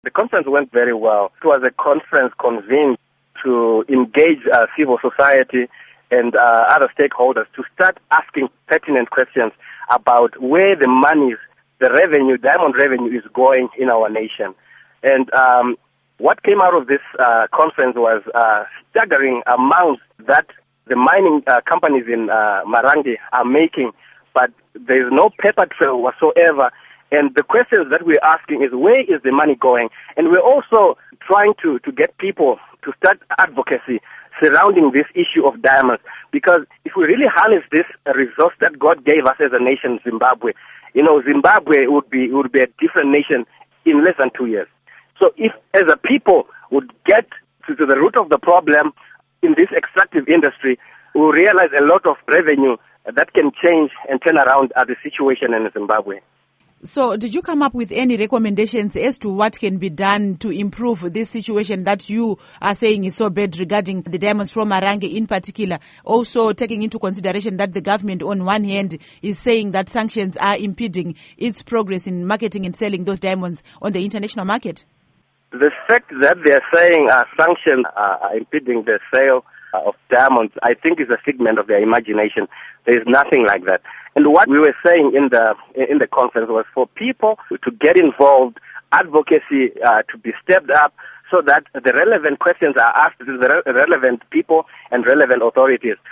Interview With Eddie Cross